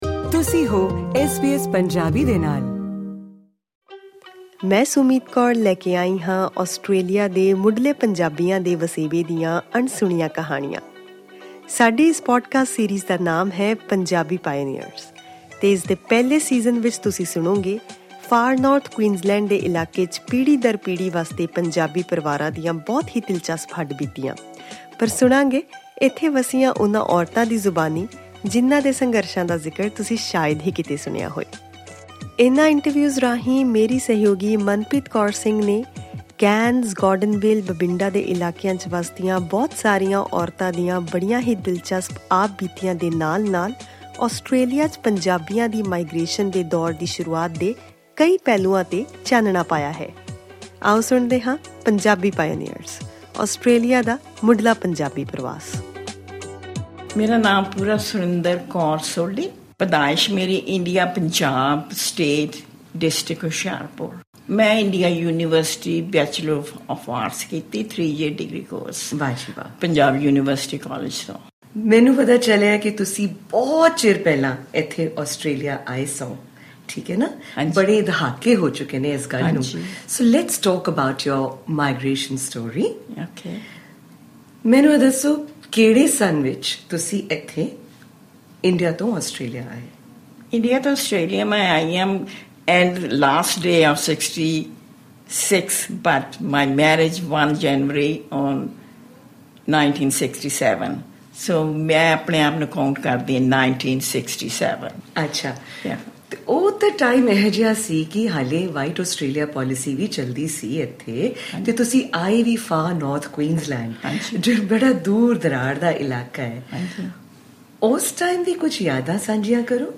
Click on the audio icon to listen to the full interview in Punjabi.